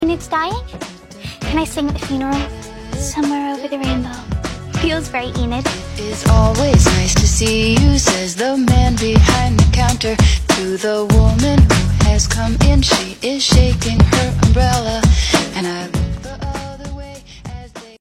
being sung